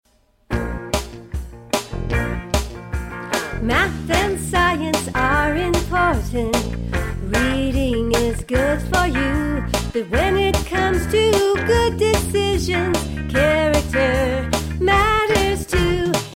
*  Catchy melodies, dumb jokes, interesting stories
(Entire CLASS faces the audience and sings:)